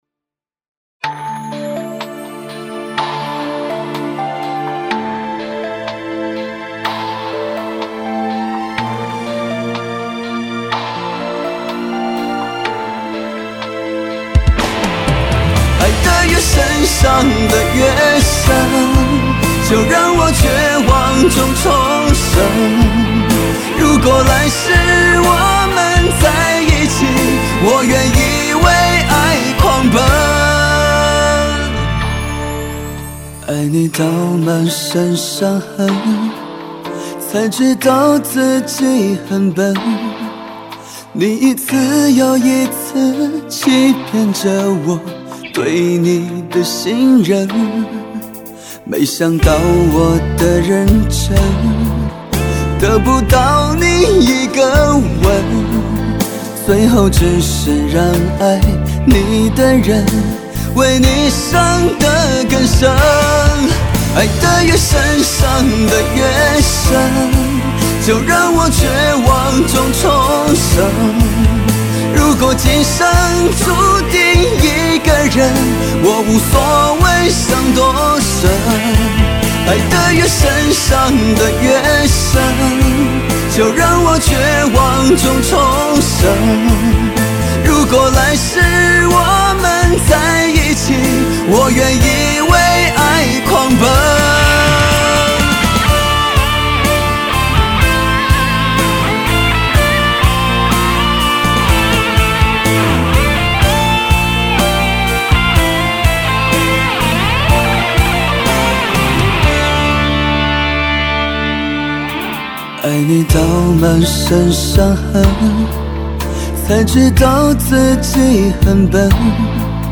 录音混音在北京现代力量录音棚完成